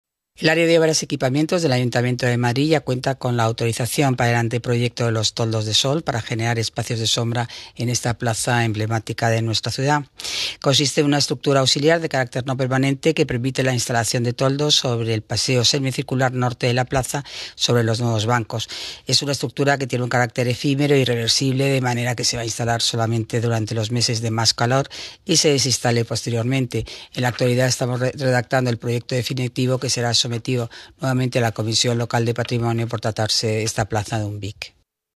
Nueva ventana:Declaraciones de Paloma García Romero, delegada de Obras y Equipamientos